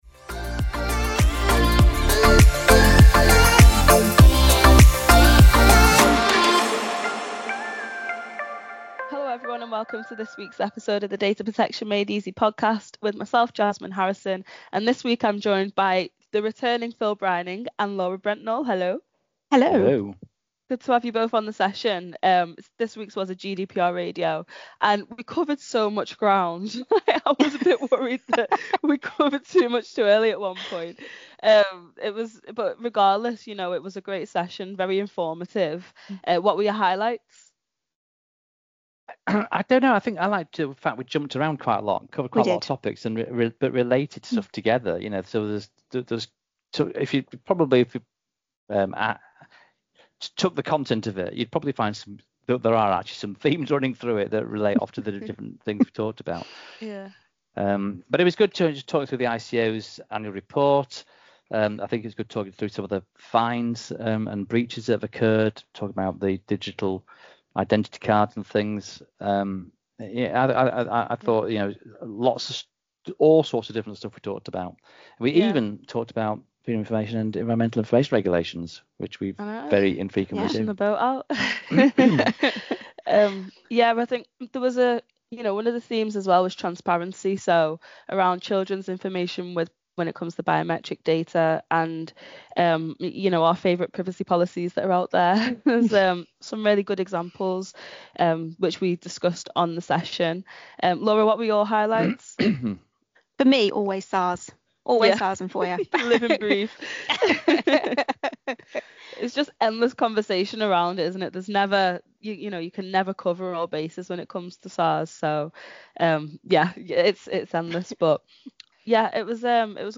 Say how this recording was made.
with our live audience of listeners